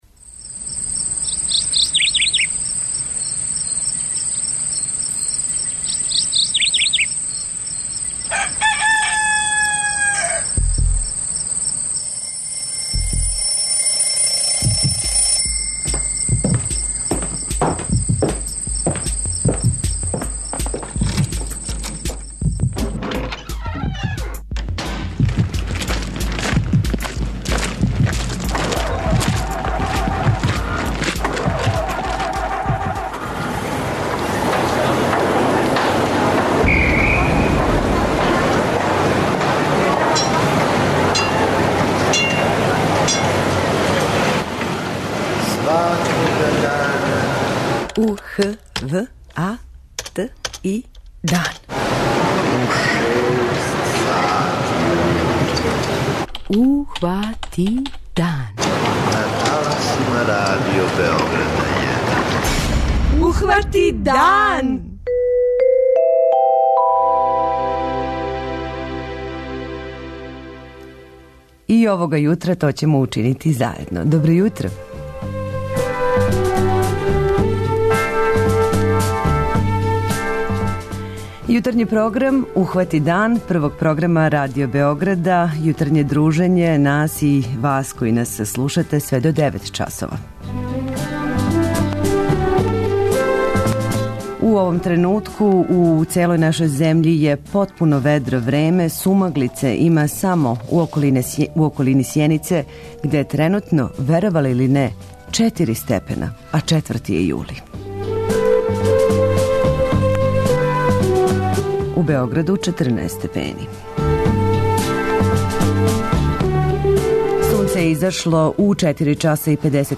Радио Београд 1, 06-09